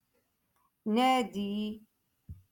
Moroccan Dialect-Rotation Five-Lesson Sixty Two